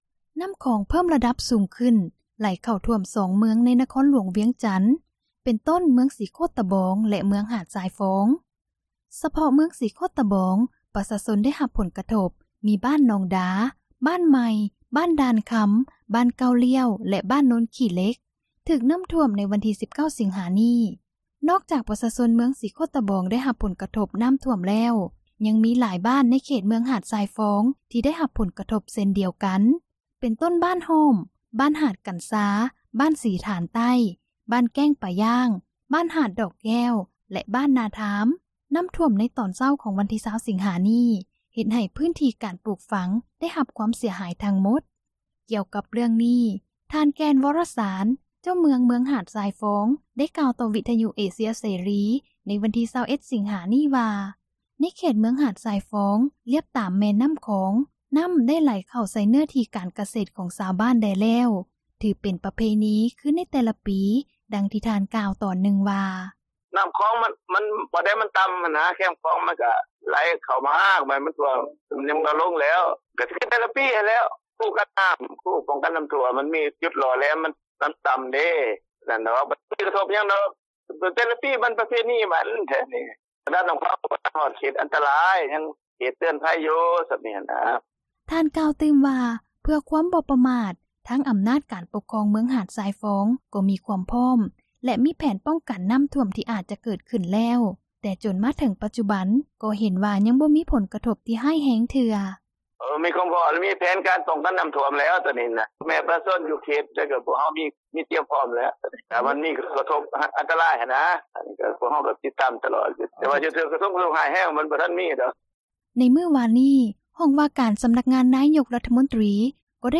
ກ່ຽວກັບເຣື້ອງນີ້ ທ່ານ ແກ່ນ ວໍຣະສານ, ເຈົ້າເມືອງ ເມືອງຫາດຊາຍຟອງ ໄດ້ກ່າວຕໍ່ເອເຊັຽເສຣີ ໃນວັນທີ່ 21 ສິງຫາ ນີ້ວ່າ ໃນເຂດເມືອງ ຫາດຊາຍຟອງ ລຽບຕາມແມ່ນ້ຳຂອງ ນ້ຳ ໄດ້ໄຫຼເຂົ້າໃສ່ເນື້ອທີ່ ການກະເສດຂອງຊາວບ້ານແດ່ແລ້ວ ຖືເປັນປະເພນີ ຄືໃນແຕ່ລະປີ, ດັ່ງ ທ່ານກ່າວຕອນນຶ່ງວ່າ: